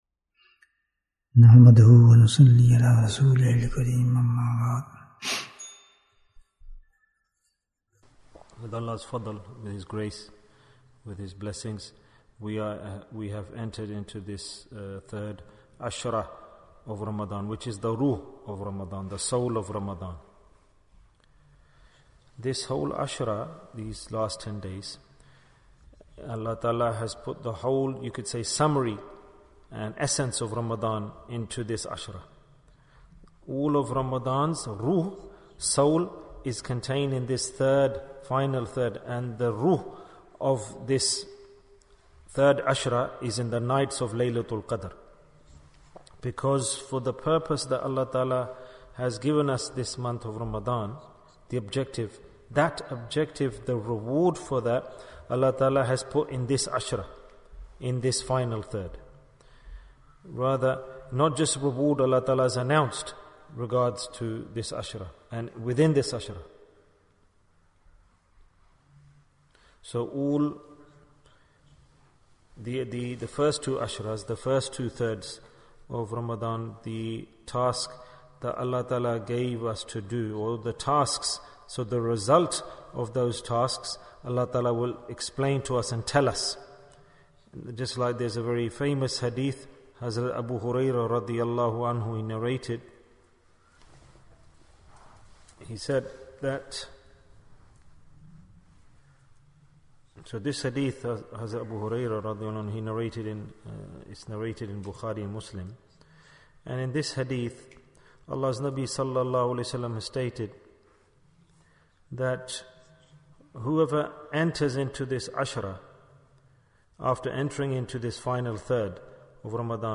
Who Obtains Layla-tul-Qadr Bayan, 40 minutes12th April, 2023